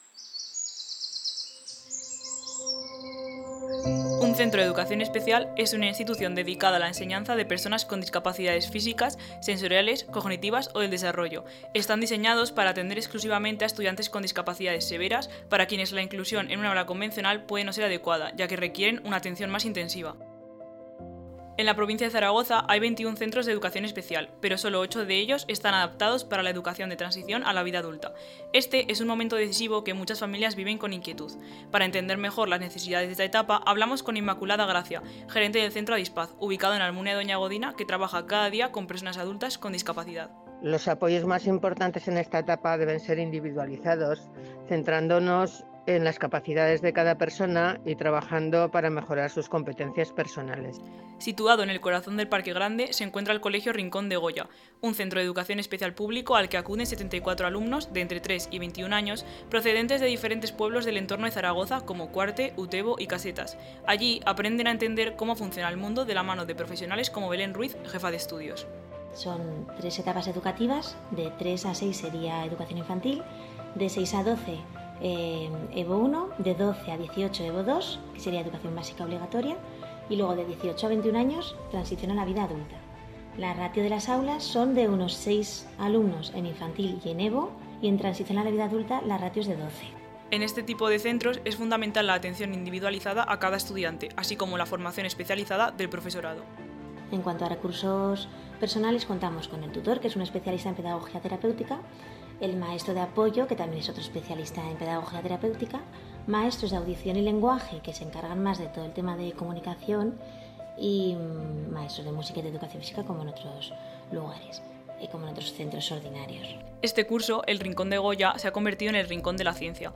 Conocemos sus etapas educativas, los apoyos sensoriales y las actividades que impulsan la autonomía de los estudiantes. A través de testimonios y sonidos ambientales, el reportaje también aborda los retos de la transición a la vida adulta y la importancia de contar con los recursos adecuados.